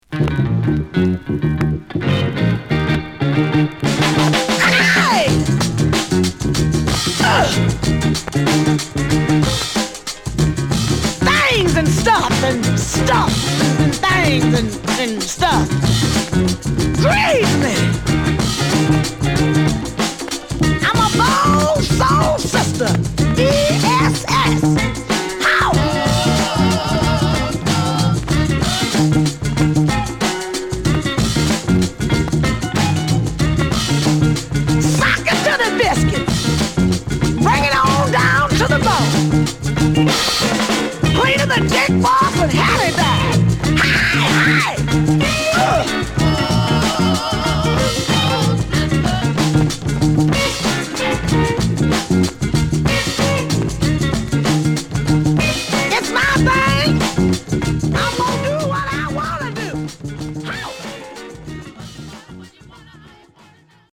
*音に出るキズ有り。